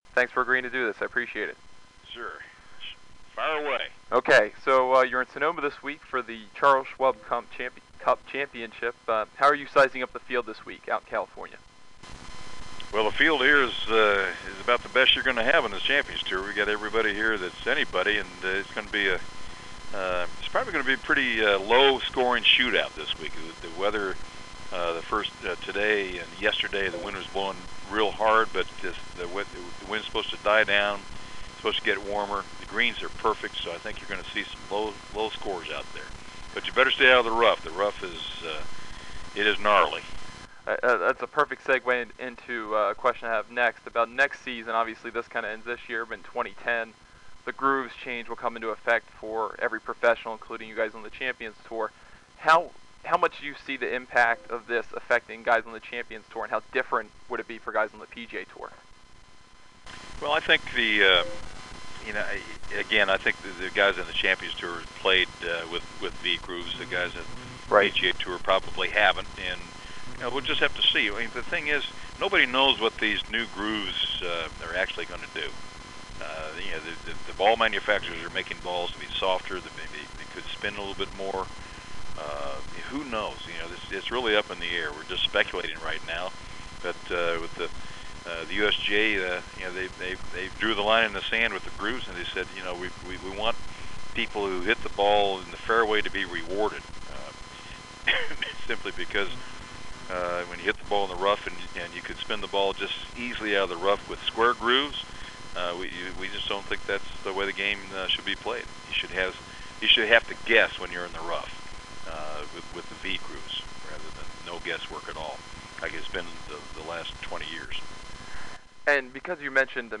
Interview with Tom Watson from Oct. 28, 2009